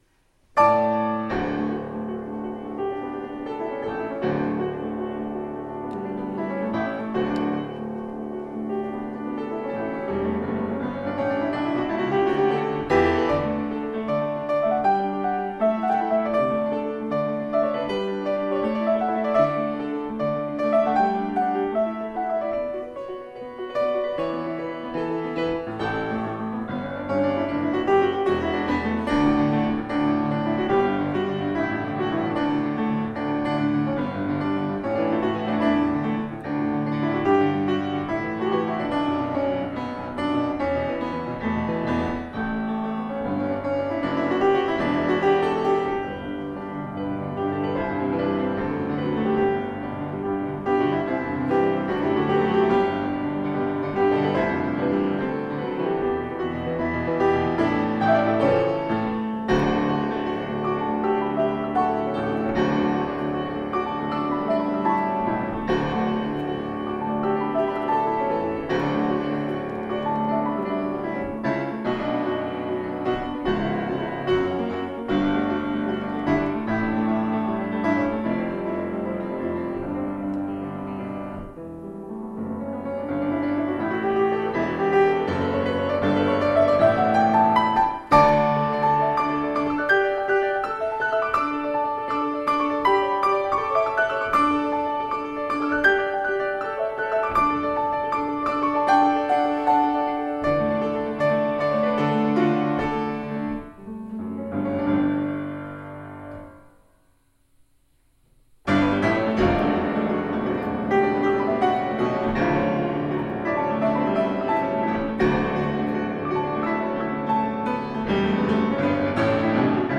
[Fuusm-l] A few boisterous pieces for balance
some rowdier tunes.